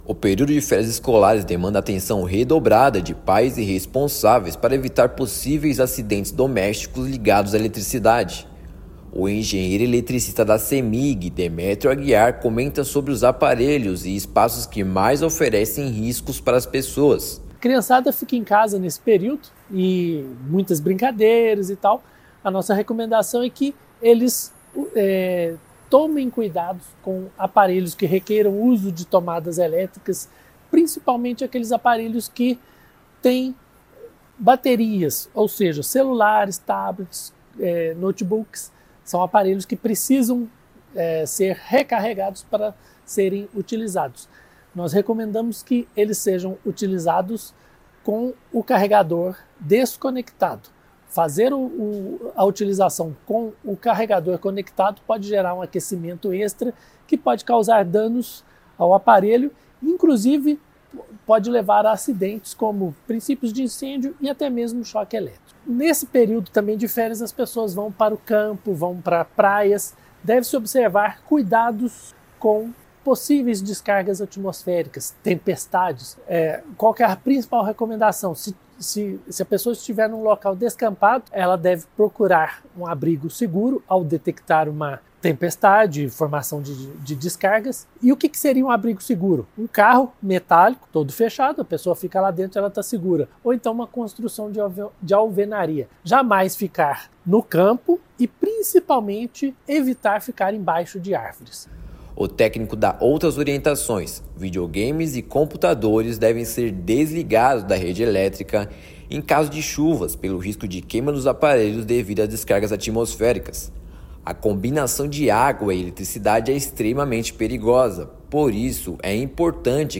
[RÁDIO] Cemig orienta sobre segurança com rede elétrica durantes férias escolares
Pais e responsáveis devem ficar ainda mais atentos para garantir a segurança das crianças e jovens no período. Ouça matéria de rádio.